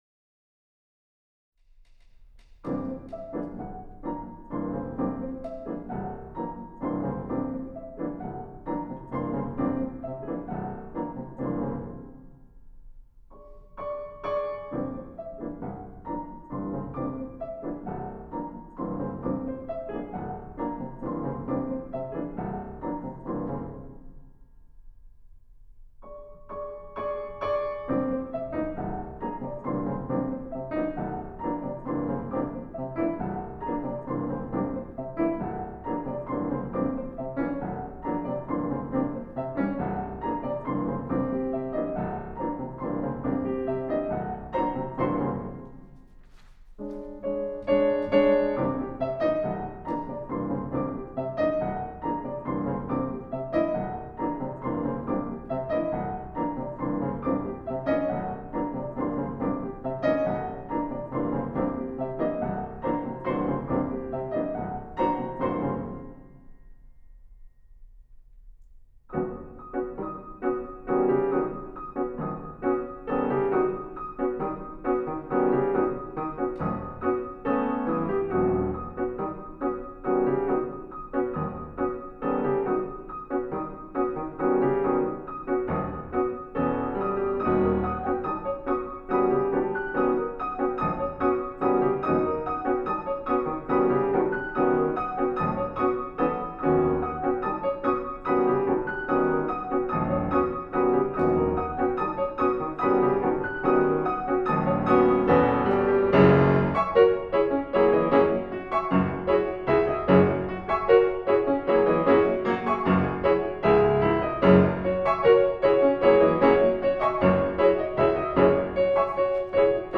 A highly rhythmic, two-piano piece based on Scott Joplin and Steve Martland, named after Martland’s piece of the same name. The recording is a bit rough
pianists